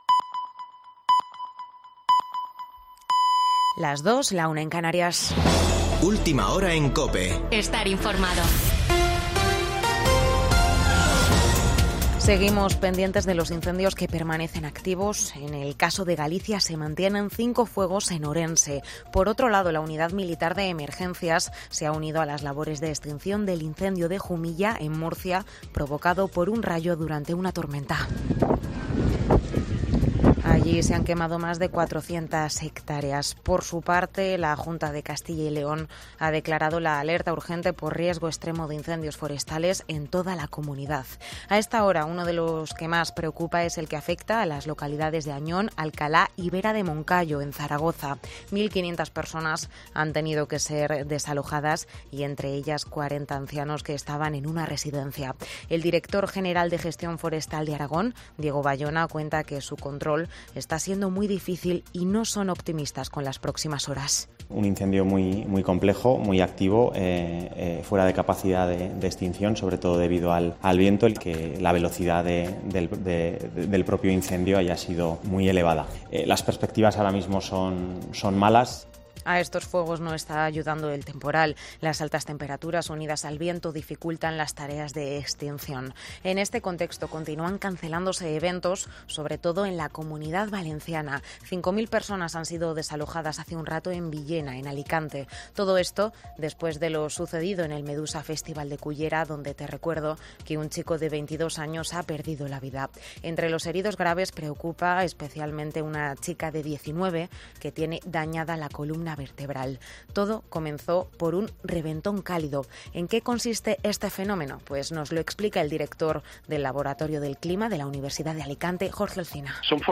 Boletín de noticias de COPE del 14 de agosto de 2022 a las 02.00 horas